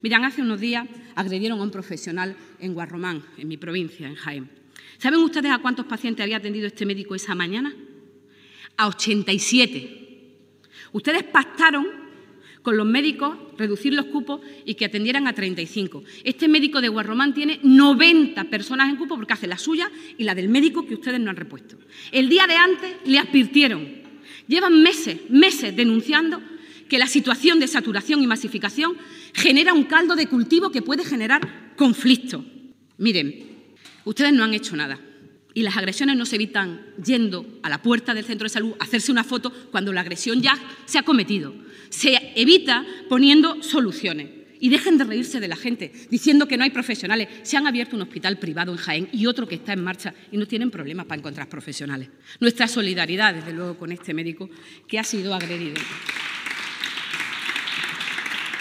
En el Parlamento de Andalucía
Cortes de sonido